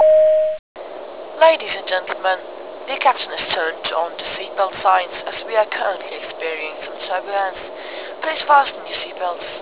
c04c12d4ba A350-family / Sounds / announce / turbulence.wav fly 2c15b8362f Initial commit ...
turbulence.wav